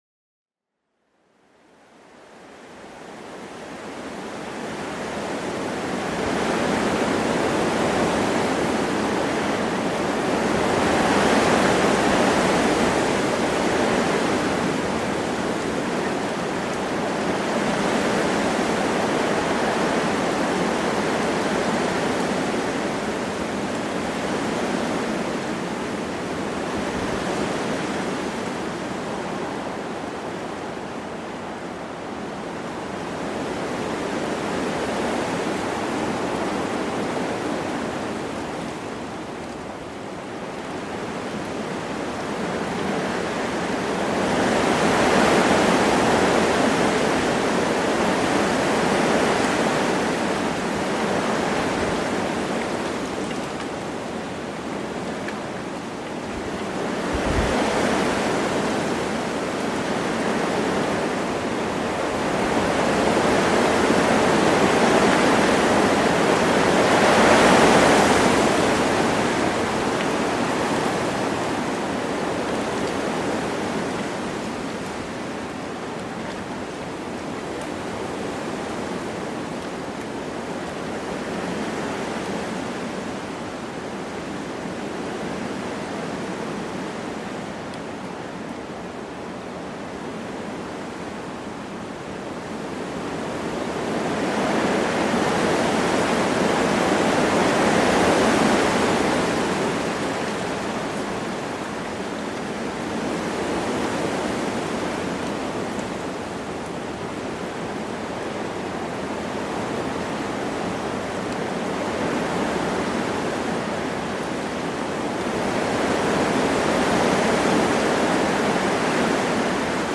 GLÜCKS-PROGRAMMIERUNG: Herbstfarben-Sound mit goldenem Rascheln